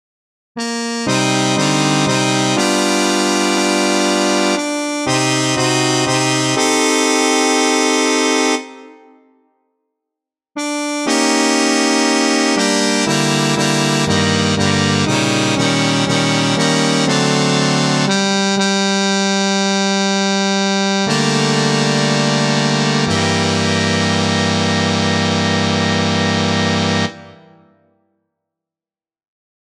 Key written in: B♭ Major
How many parts: 4
Type: Barbershop
Comments: Jazzy minor-key Halloween tag.
All Parts mix: